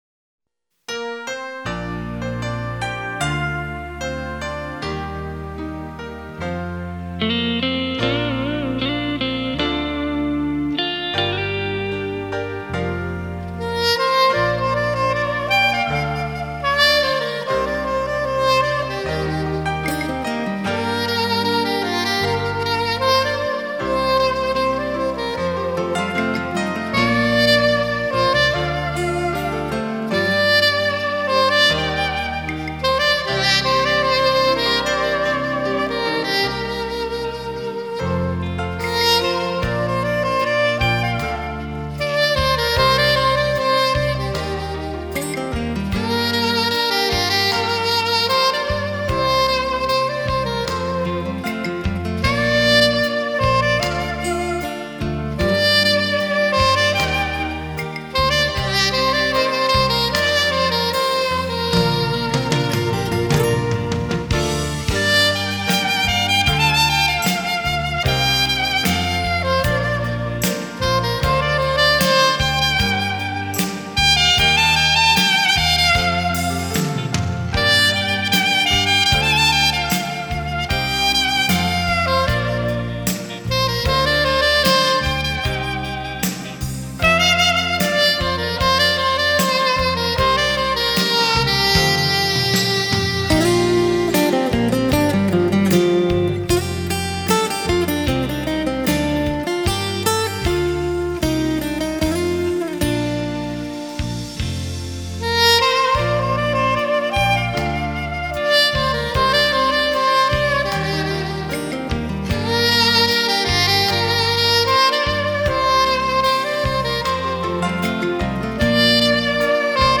喜欢萨克斯妩媚的音色感觉很飘然...